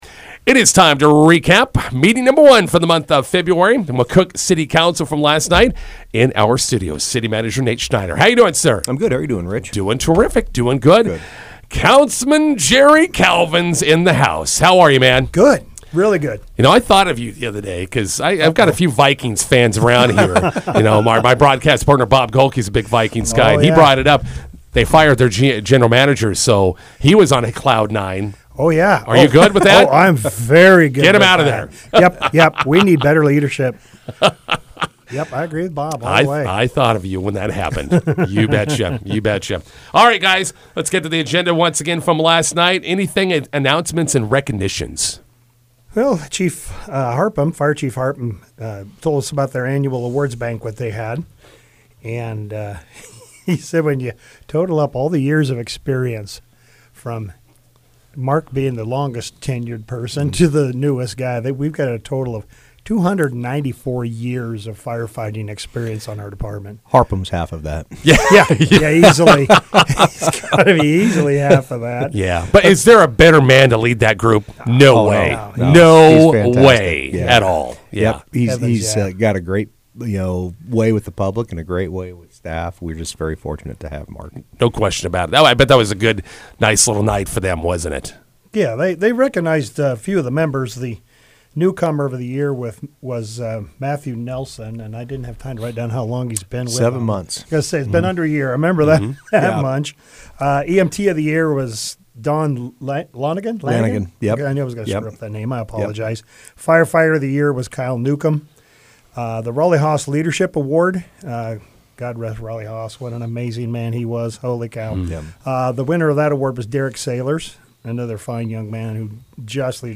INTERVIEW: McCook City Council meeting recap with City Manager Nate Schneider and Councilman Jerry Calvin.